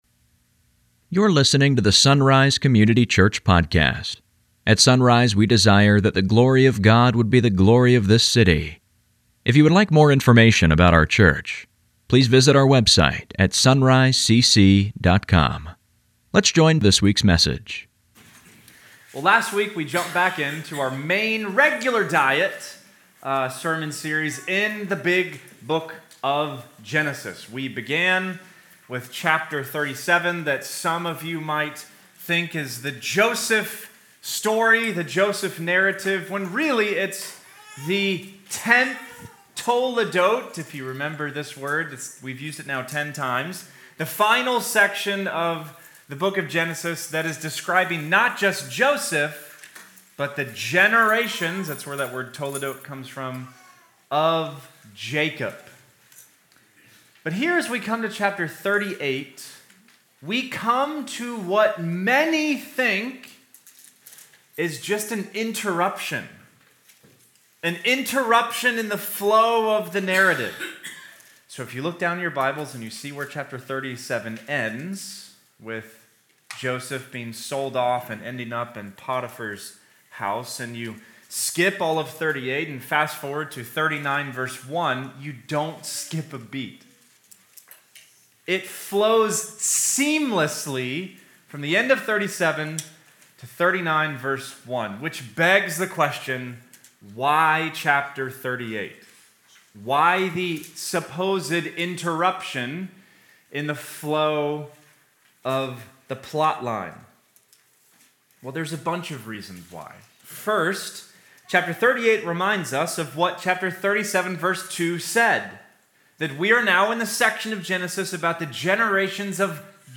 Sunday Mornings | SonRise Community Church